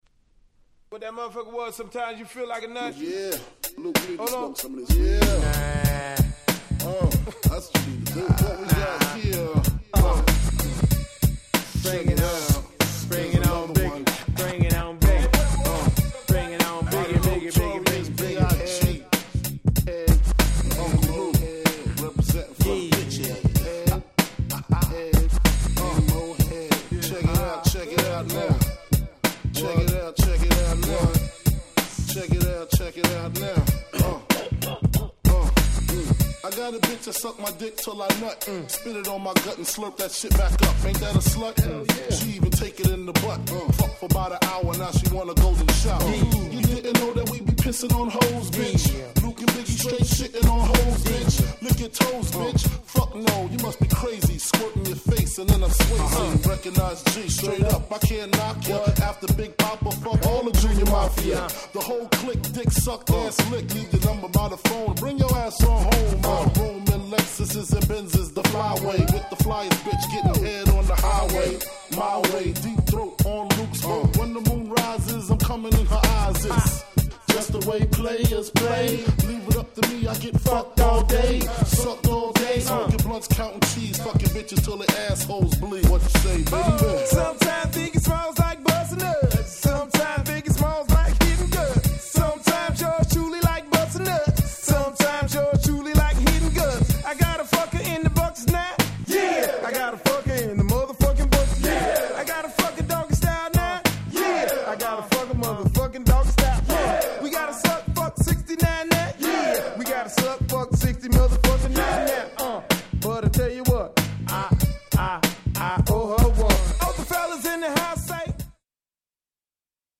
ルーク ビギー  ノトリアス ビーアイジー ノートリアス 90's Boom Bap ブーンバップ プロモオンリー